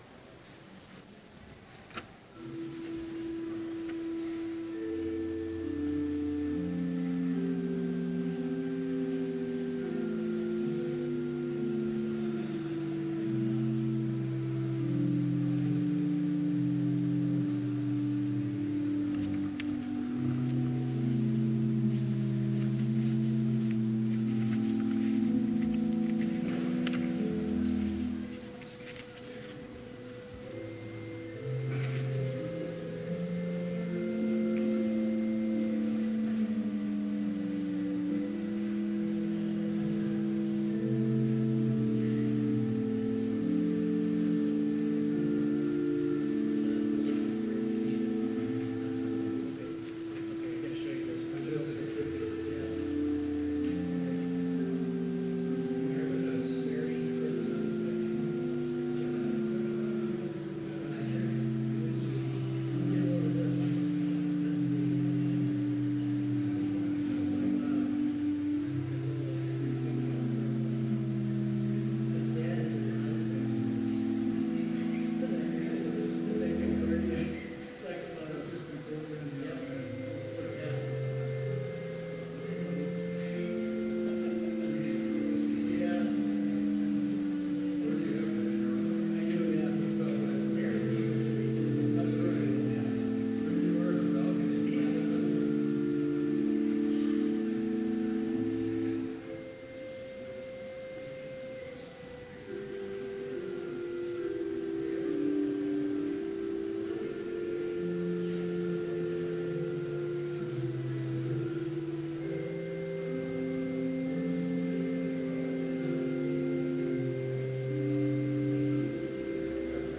Complete service audio for Chapel - April 4, 2017